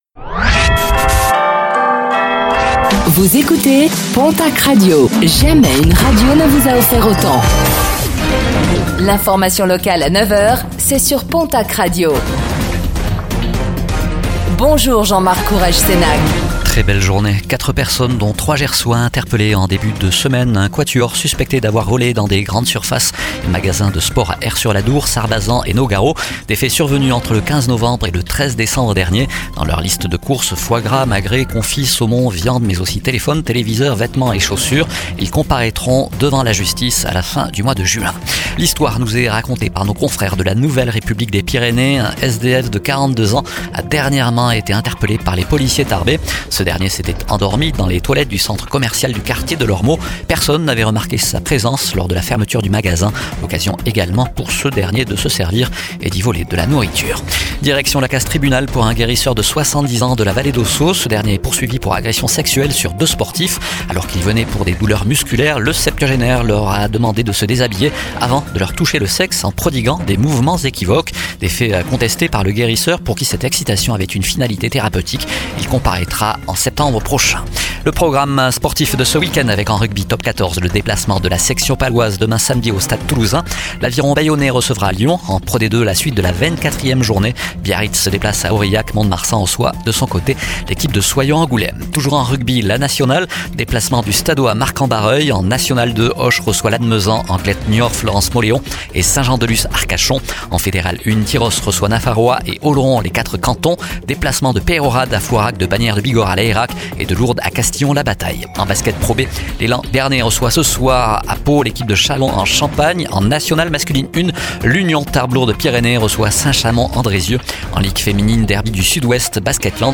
Infos | Vendredi 28 mars 2025